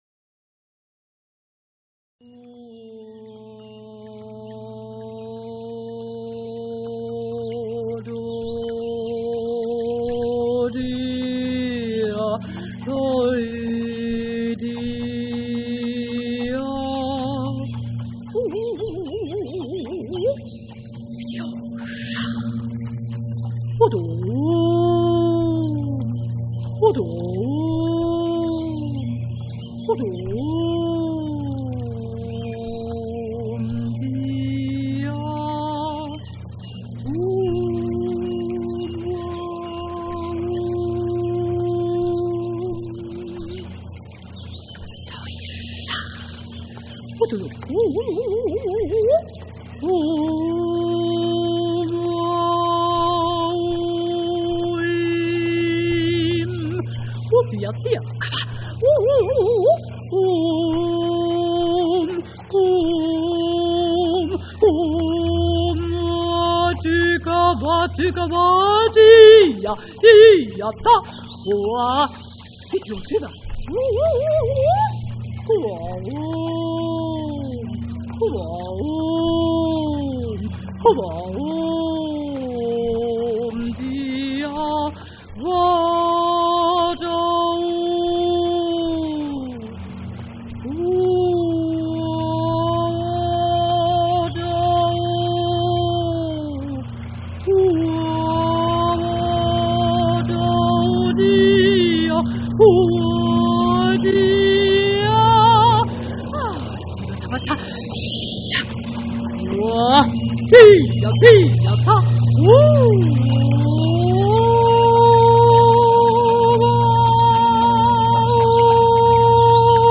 für Sopran und Tonband • ohne Text • 12´ • pub.